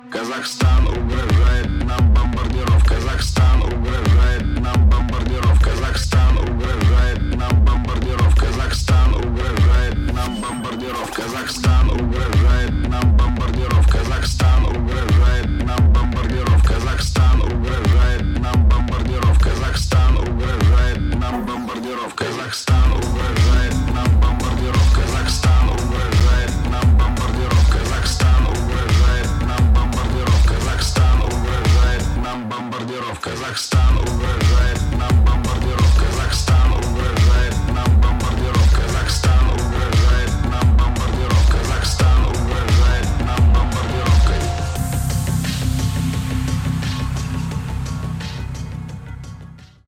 Песня ремикс (49 сек)